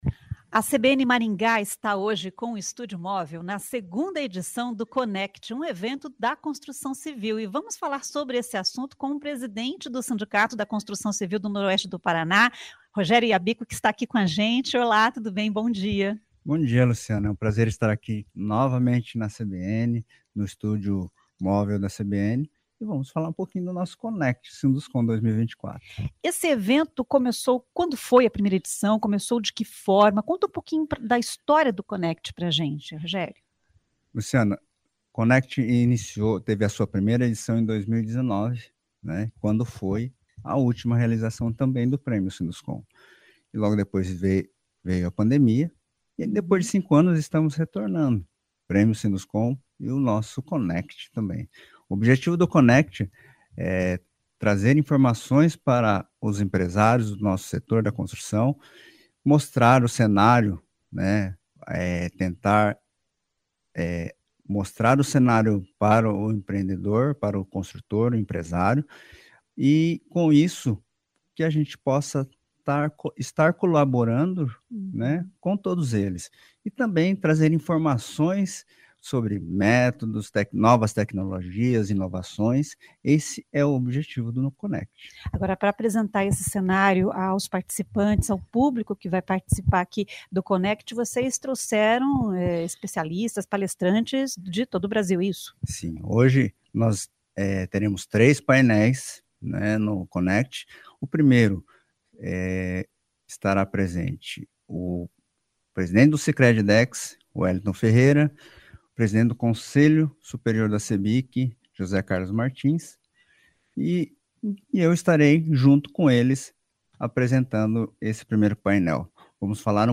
Estúdio Móvel CBN